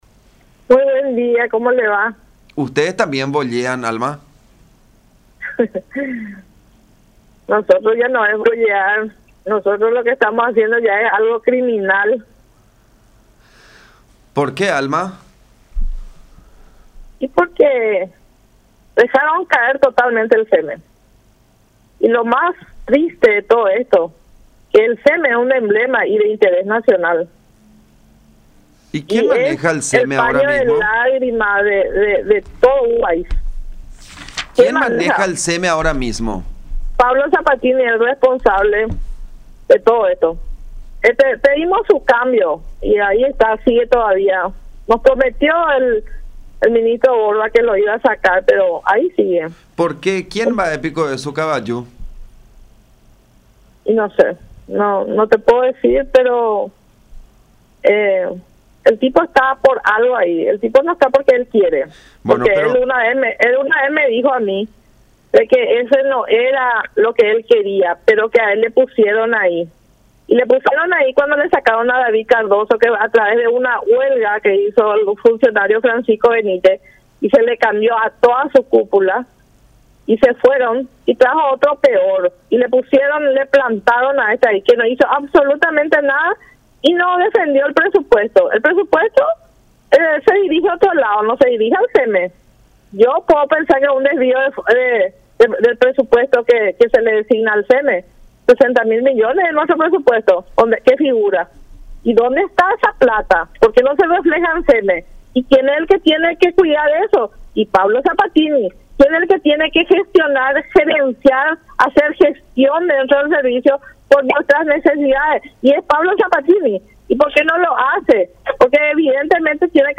en comunicación con La Unión R800 AM